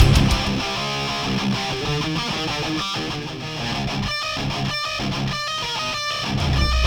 похоже на ревер, но едва заметный